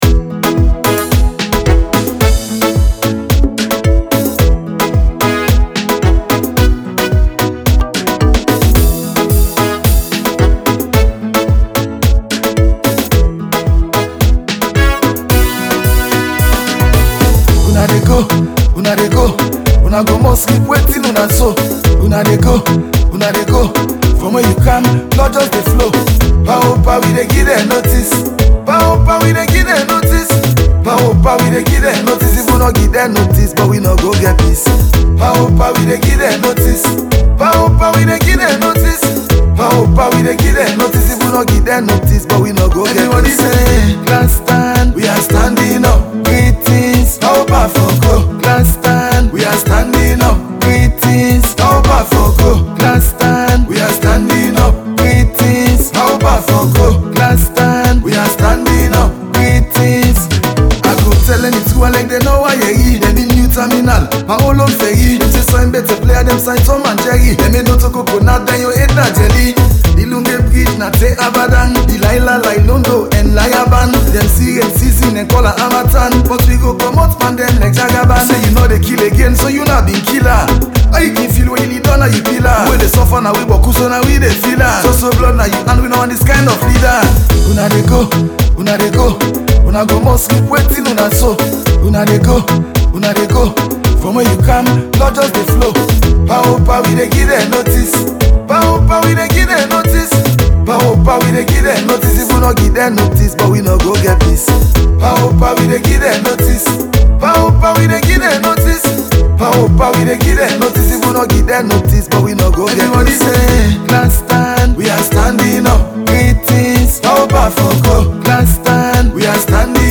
political song